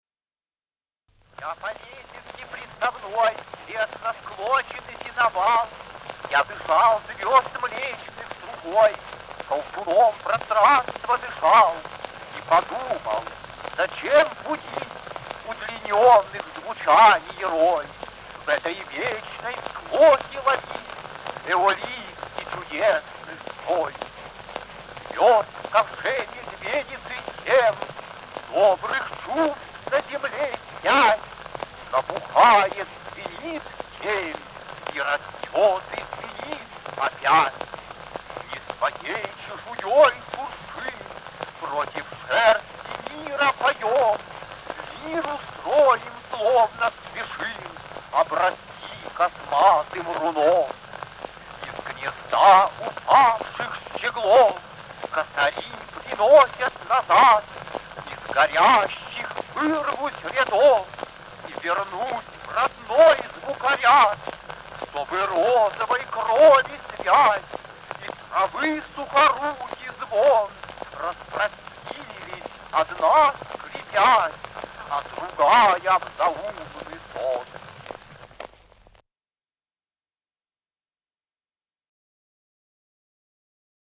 4. «Читает Осип Мандельштам – Я по лесенке приставной…» /